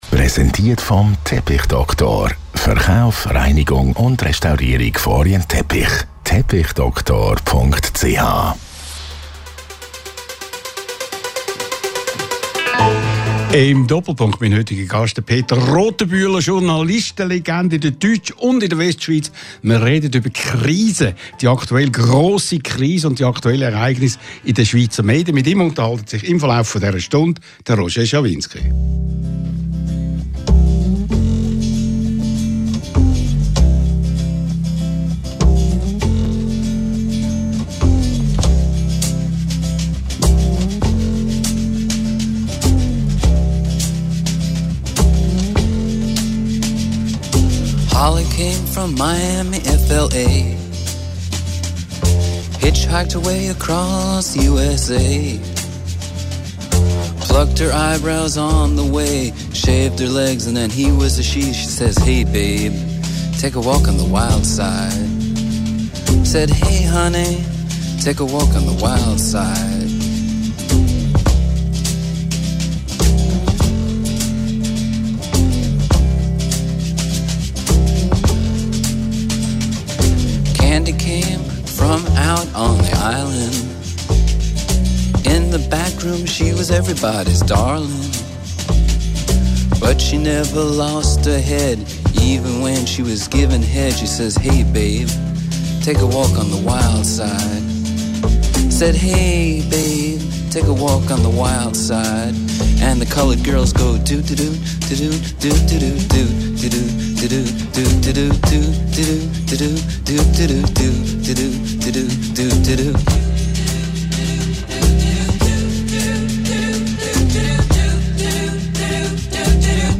In der heutigen Sendung ist die Journalistenlegende Peter Rothenbühler zu Gast bei Radio 1-Chef Roger Schawinski. Im Gespräch geht es um die aktuelle Lage im Ringier-Medienhaus sowie um die Frage, wie realistisch die Untergangsszenarien für die Schweizer Medienlandschaft eingeschätzt werden.